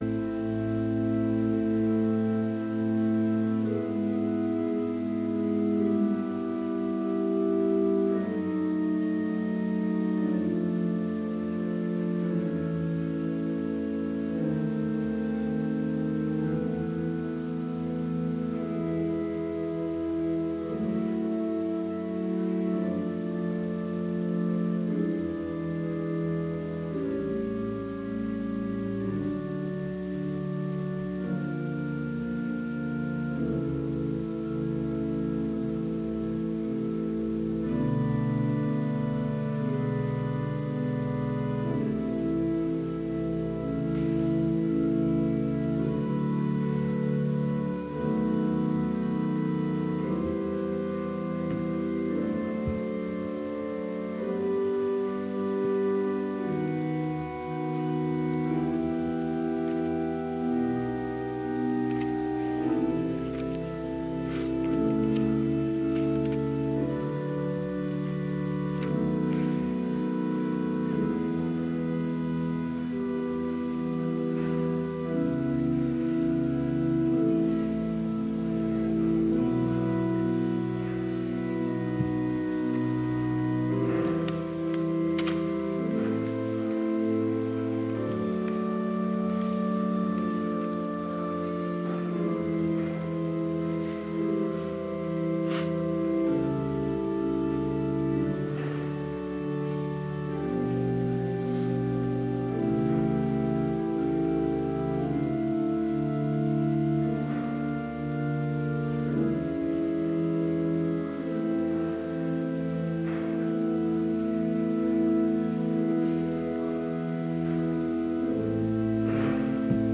Complete service audio for Chapel - March 15, 2016
Order of Service Prelude Hymn 333, vv. 1-3, Christ, the Life of All the Living Reading: Hebrews 9:13-14 Devotion Prayer Hymn 333, vv. 6 & 7, Thou has suffered… Blessing Postlude